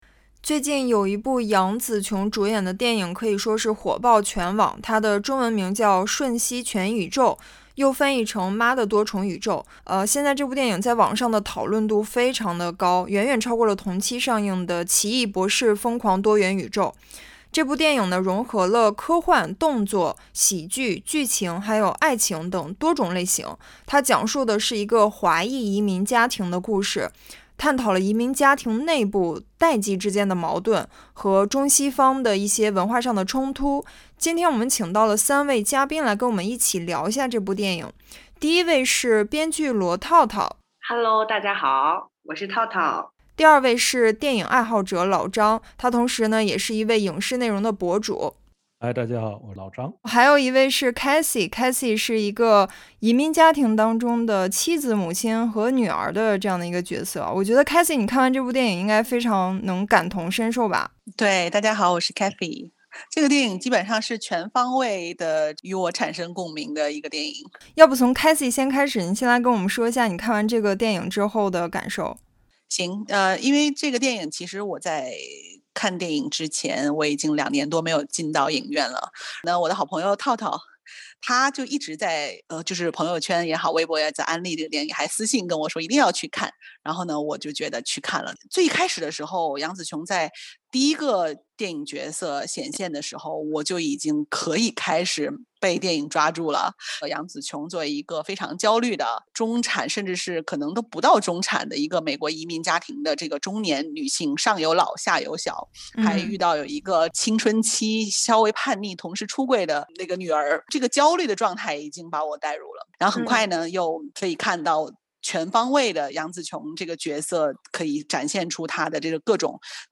它是一部集科幻，动作，喜剧，剧情等多种类型于一体的影片，讲述了一个华裔移民家庭的故事，探讨了移民家庭内部代际之间的矛盾和中西文化冲突。本期我们请到了三位嘉宾